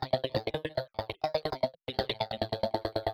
RobotGossip.wav